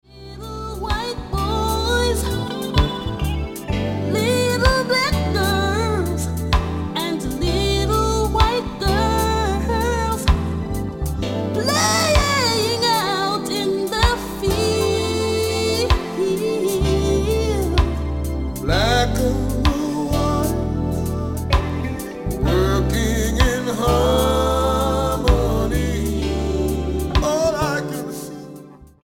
STYLE: Reggae